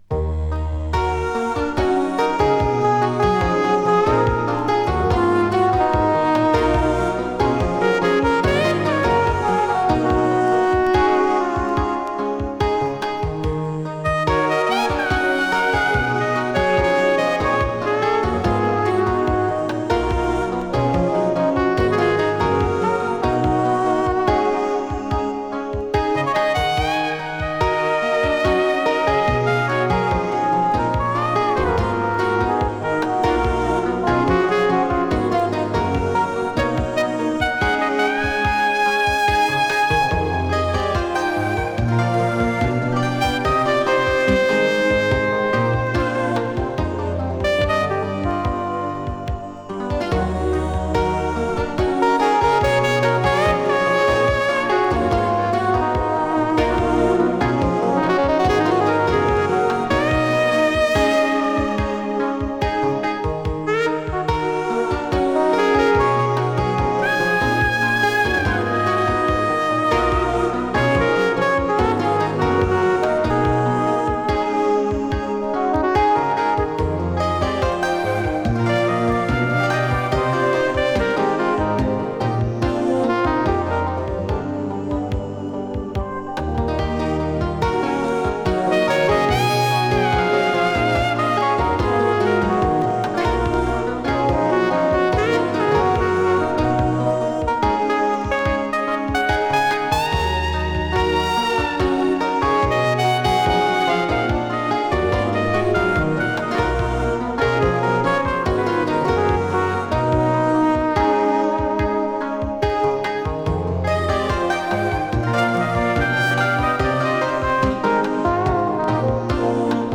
スイス産エレクトロニクス・フュージョン！
【FUSION】【ELECTRONICS】【SWITZERLAND】